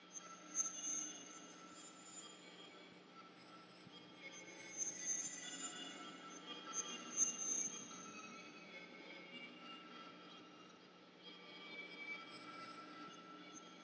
Env_Treasure chest_v2.wav